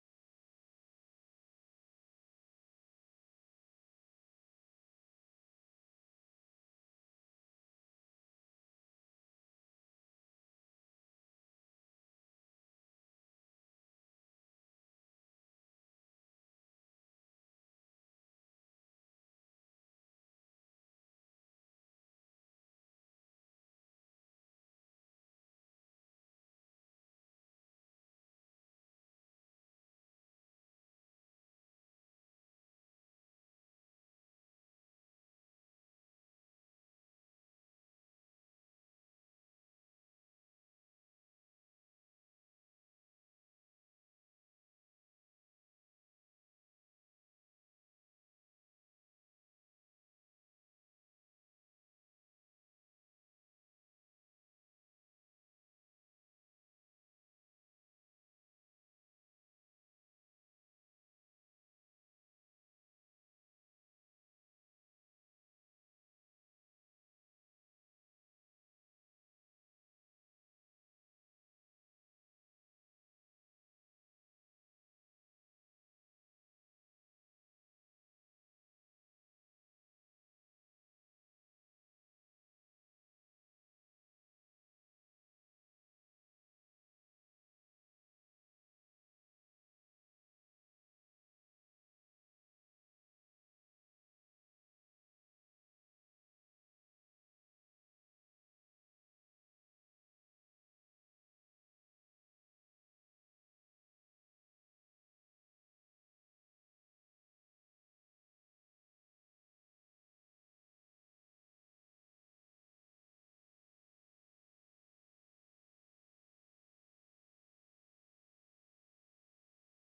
Culte du dimanche 06 avril 2025, prédication